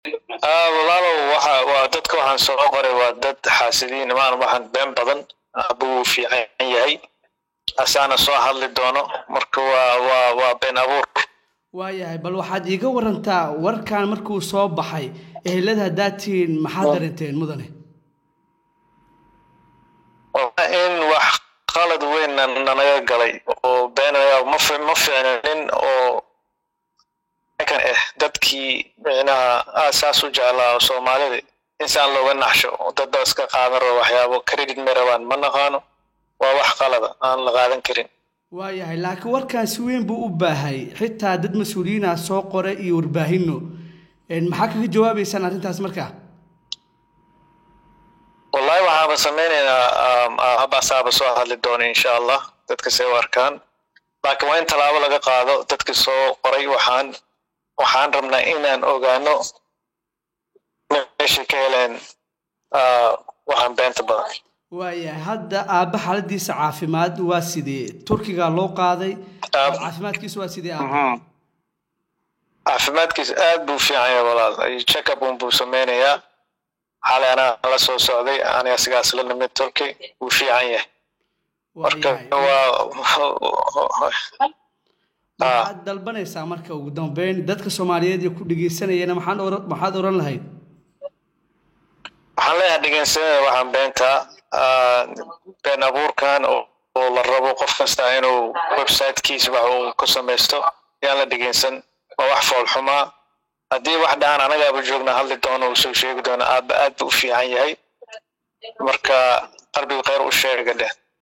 wareysi kooban